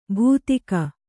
♪ bhūtika